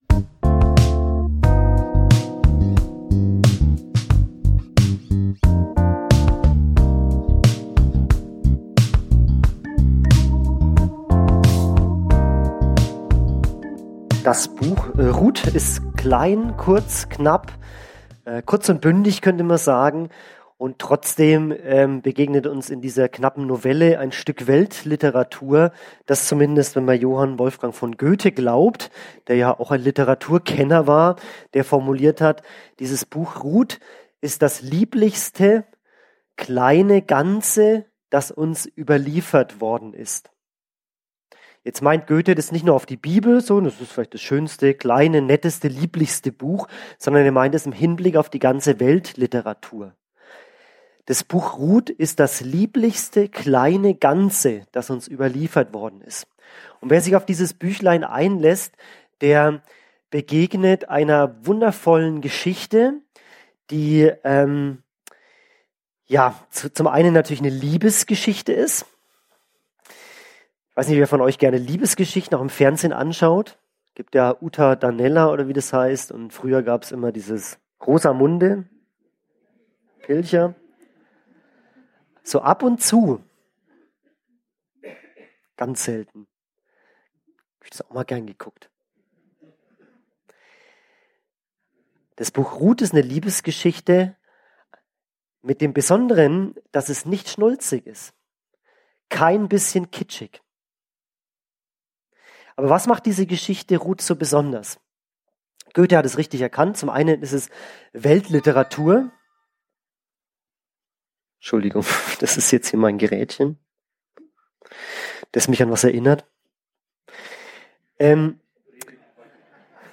Die Texte zur Predigt über das Buch Rut finden sich zum Beispiel hier.